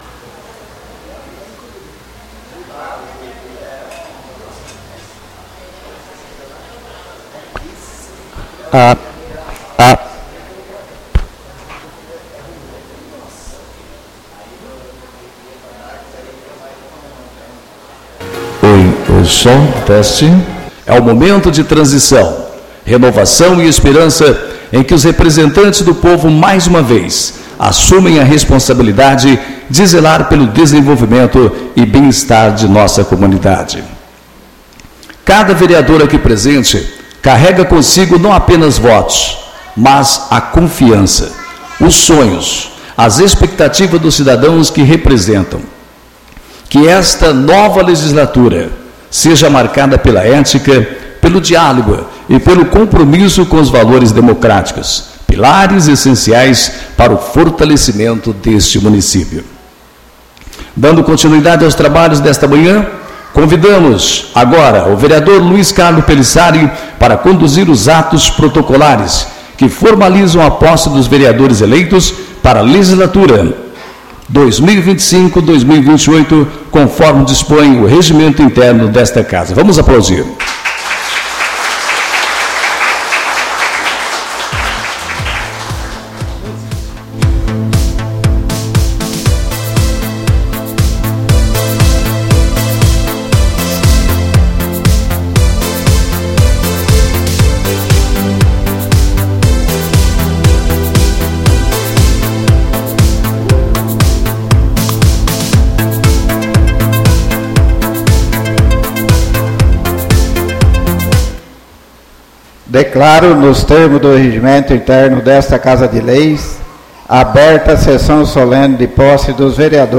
ÁUDIO DA SESSÃO DE POSSE DOS VEREADORES, PREFEITO E VICE E ELEIÇÃO DA MESA DIRETORA — CÂMARA MUNICIPAL DE NOVA SANTA HELENA - MT